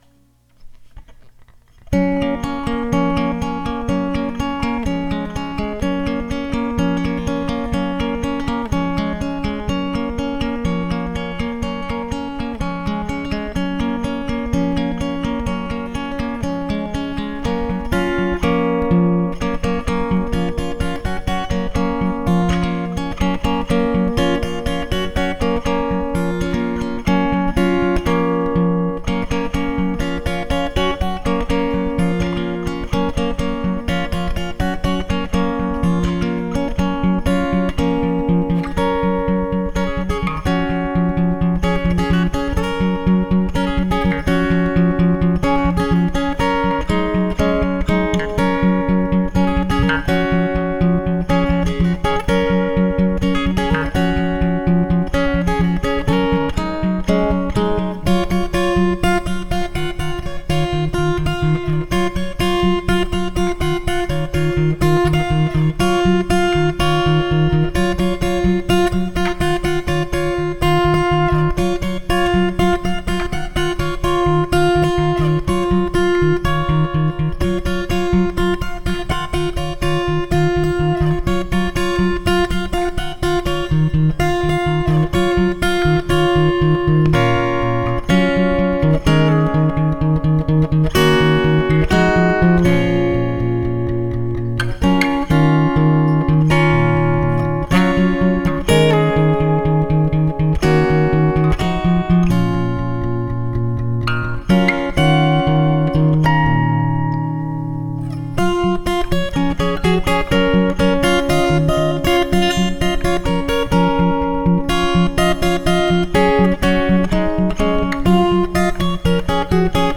Guitar Audio Files